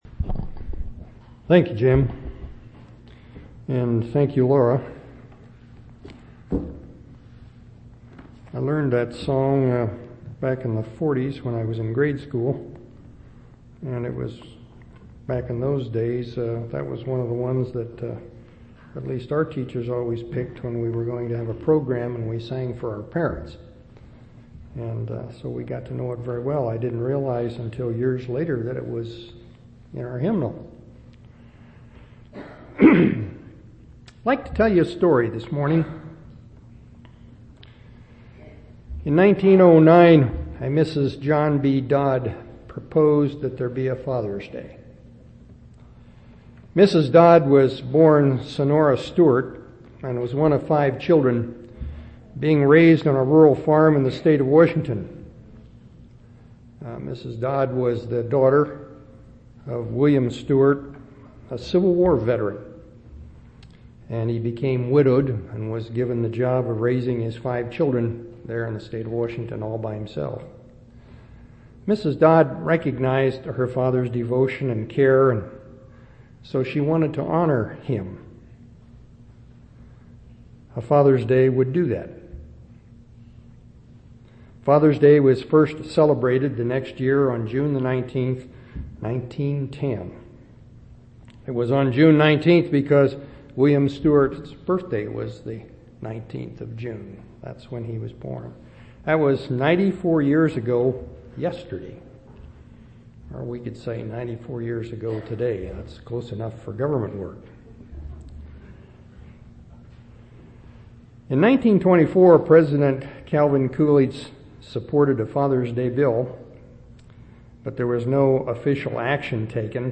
Father’s Day Sermon – Resources Home
6/20/2004 Location: Temple Lot Local Event